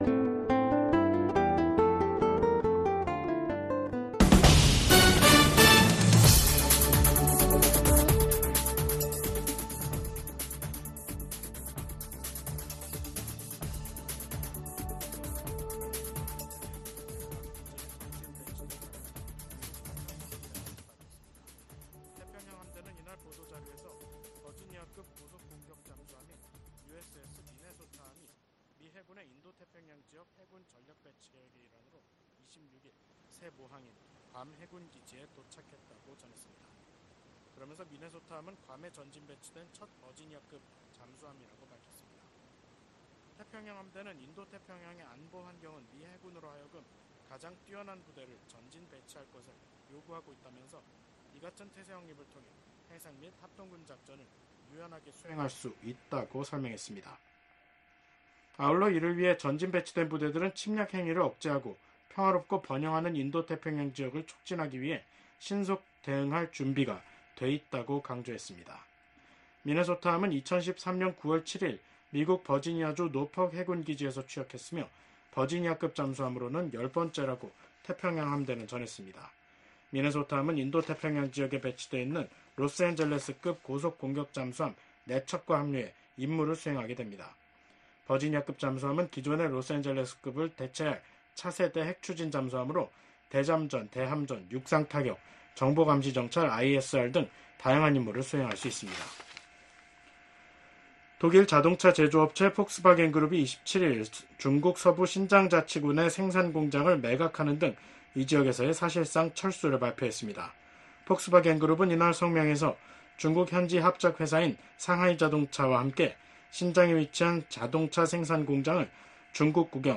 VOA 한국어 간판 뉴스 프로그램 '뉴스 투데이', 2024년 11월 27일 3부 방송입니다. 미국 국무장관은 미국 등 주요 7개국이 북한이 병력 파병 대가로 러시아로부터 핵과 미사일 기술을 넘겨받을 가능성을 주시하고 있다면서, 중국이 대북 영향력을 발휘하지 않으면 미국은 한반도에서 억지력 강화를 위한 추가 조치를 취할 것이라고 경고했습니다.